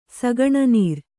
♪ sagaṇa nīr